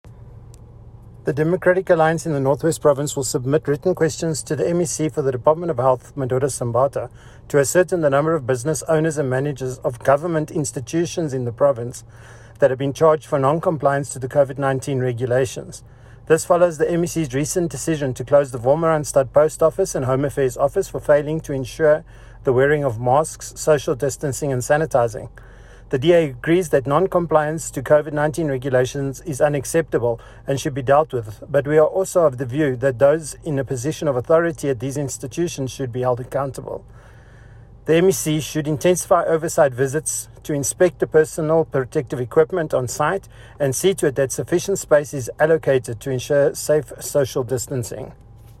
Note to Editors: Kindly find attached soundbites in
English and Afrikaans by DA North West Spokesperson on Health, Gavin Edwards.